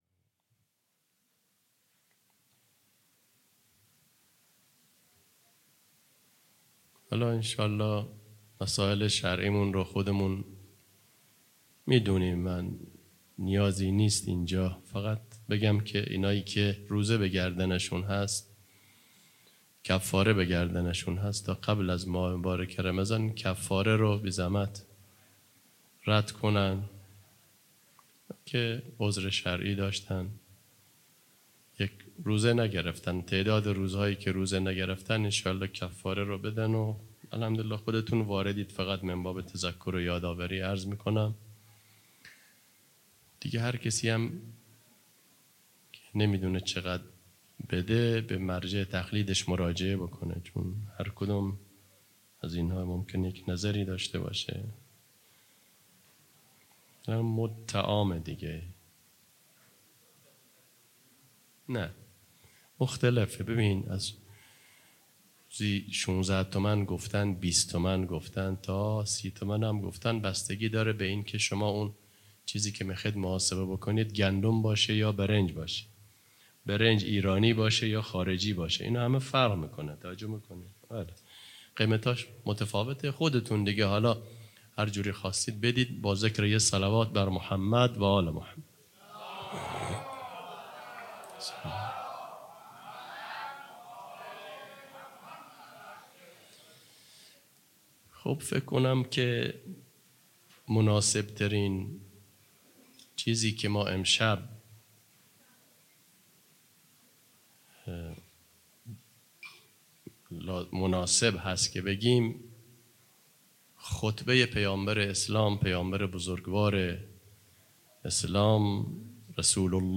خیمه گاه - هیئت محبان الحسین علیه السلام مسگرآباد - سخنرانی